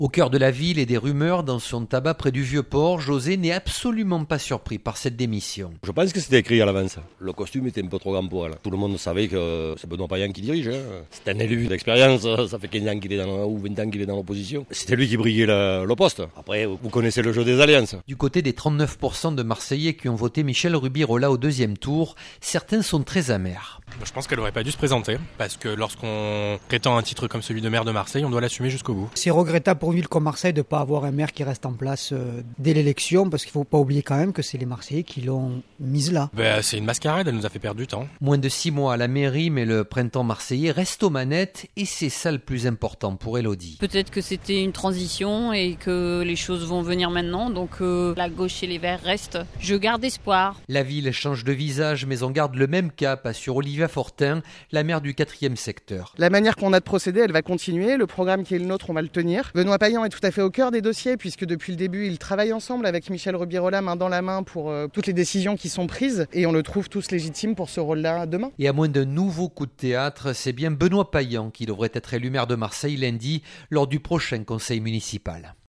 Des Marseillais peu surpris... Reportage